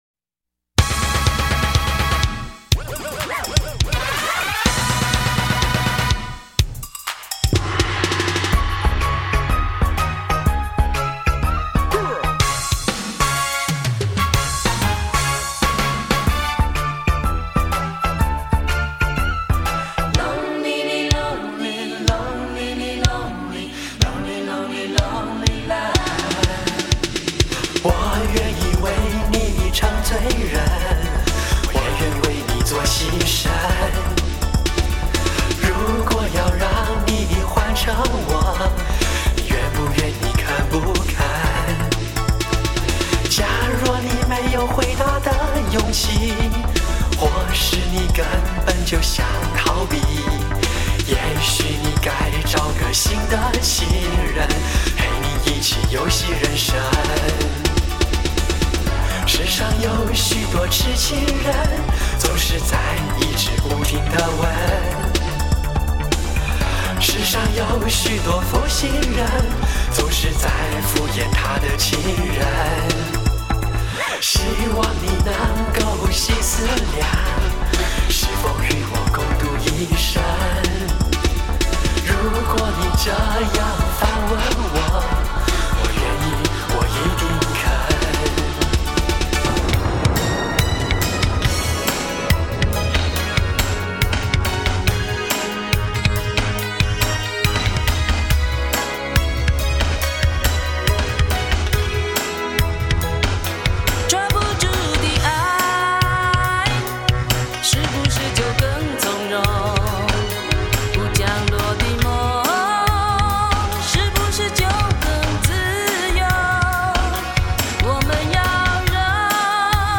45就是 采45转快转的方式演唱串联当红歌曲的组曲 记录着70.80年代台湾流行乐史